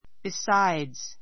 besides A2 bisáidz ビ サ イ ヅ 前置詞 ～のほかに We learn French besides English.